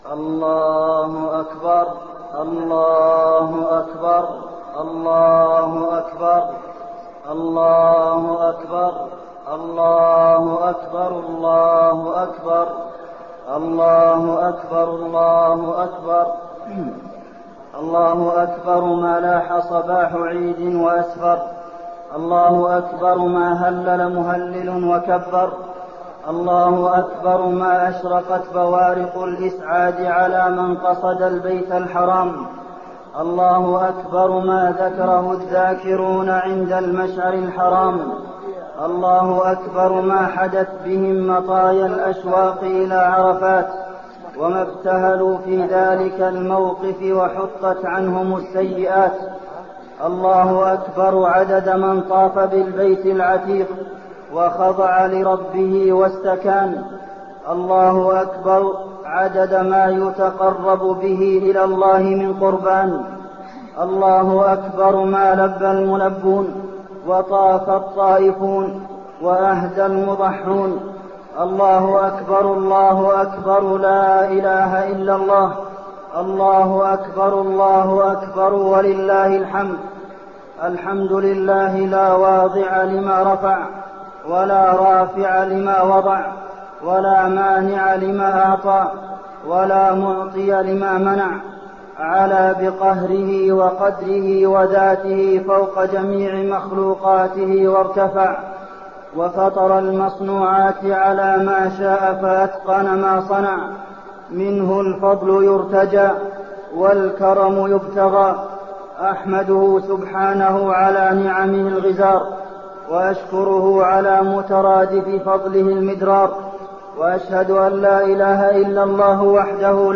خطبة عيد الأضحى - المدينة - الشيخ عبدالمحسن القاسم - الموقع الرسمي لرئاسة الشؤون الدينية بالمسجد النبوي والمسجد الحرام
المكان: المسجد النبوي